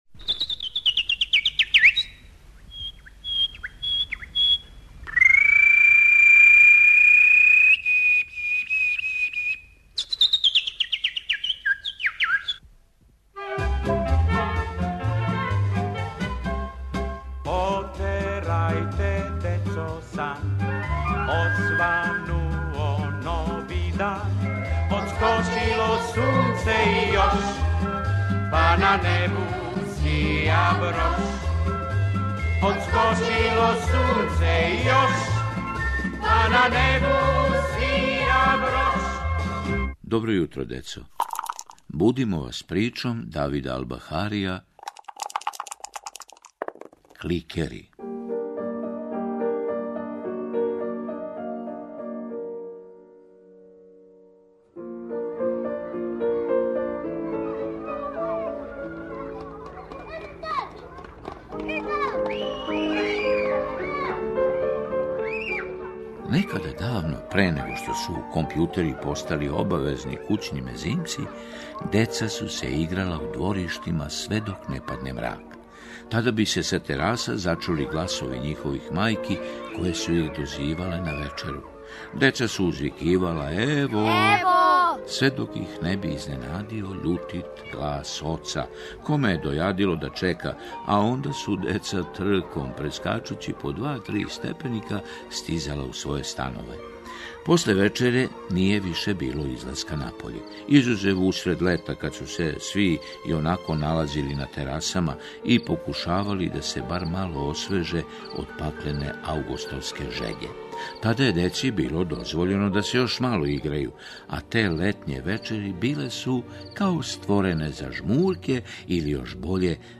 У петоминутној форми Добро јутро, децо! пружа најмлађима свакога јутра причу за децу адаптирану за радио, или неку другу забавну и поучну епизоду. У снимању емисија учествују глумци и мали глумци, режисери и најбољи тон-мајстори.
Шест емисија у овој компилацији раздвојено је кратким паузама, а редослед је идентичан редоследу седмичног емитовања.